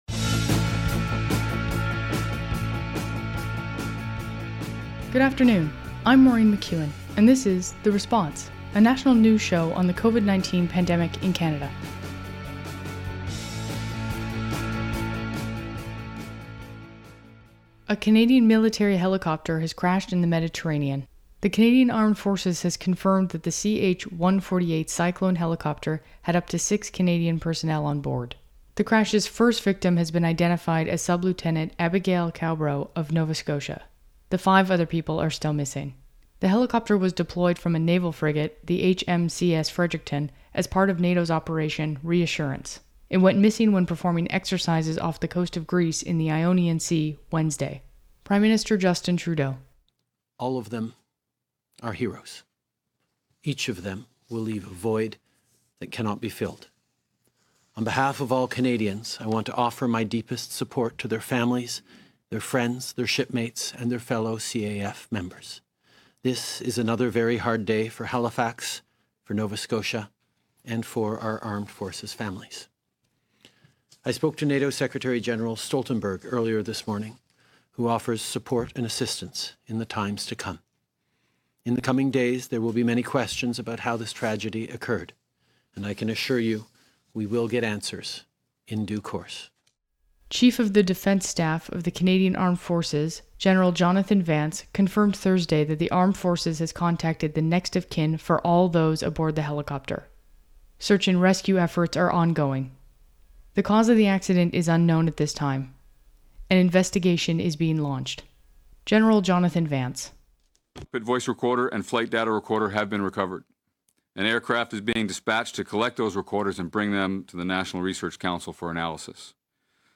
National News Show on COVID-19
Type: News Reports
192kbps Stereo